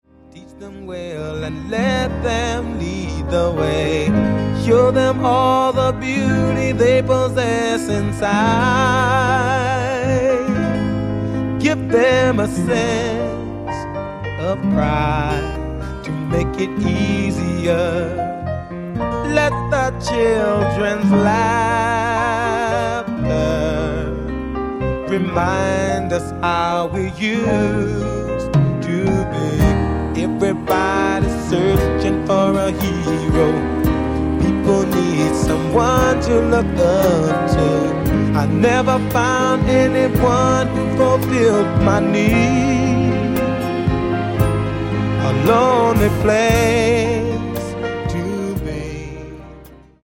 VOLUME :: 69 :: - LOVE BALLADS -